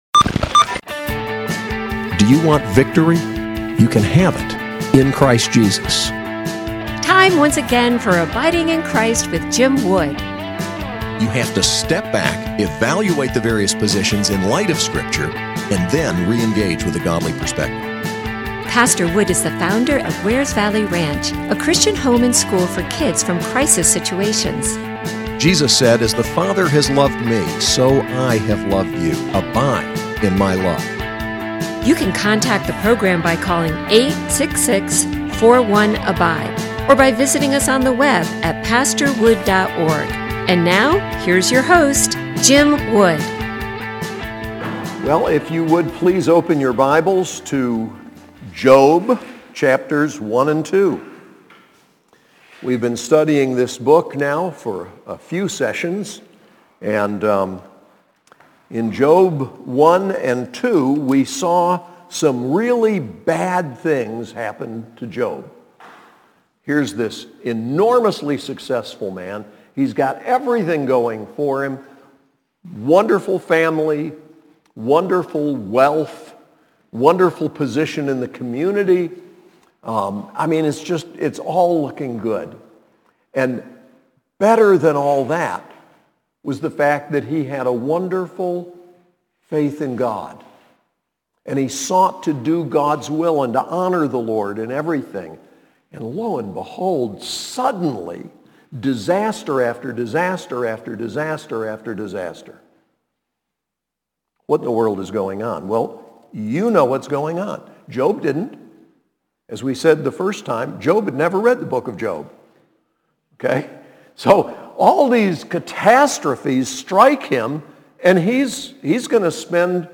SAS Chapel